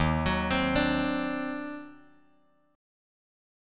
EbmM13 chord